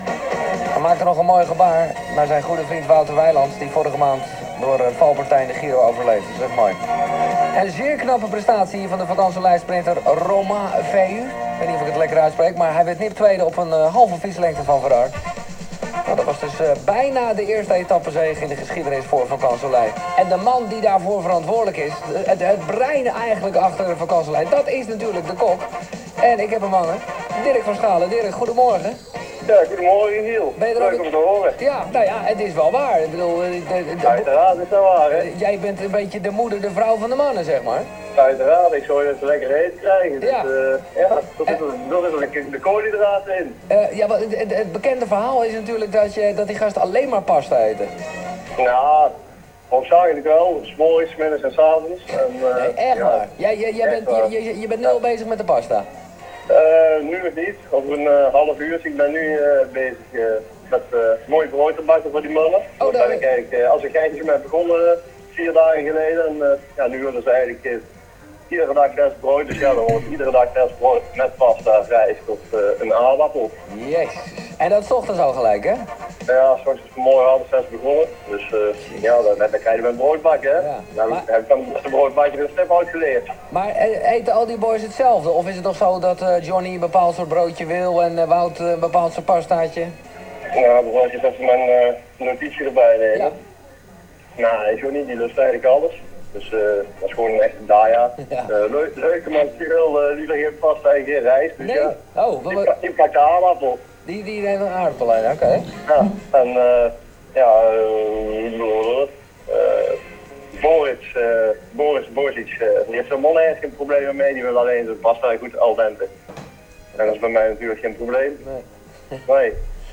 Als kok op locatie mee met de to Luister hier naar het interview wat Giel Beelen met mij had.